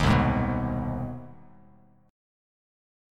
CmM7#5 chord